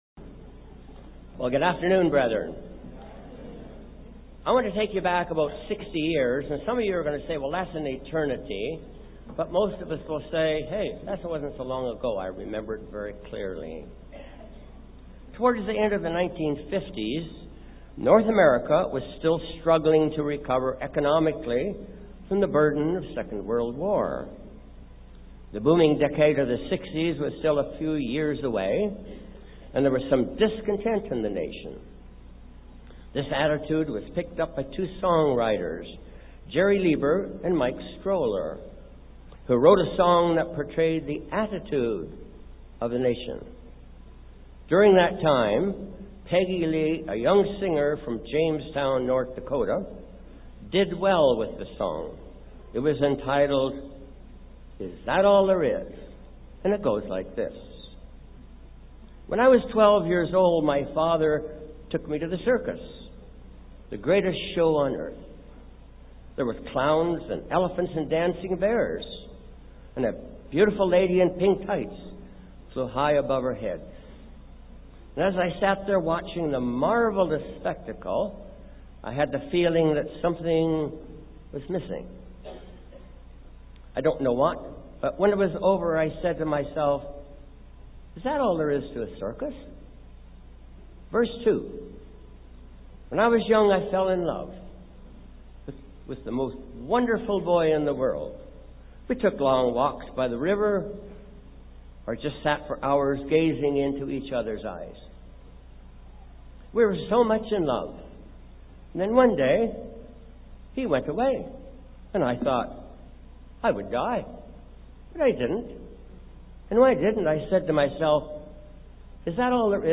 This sermon was given at the Jamaica 2013 Feast site.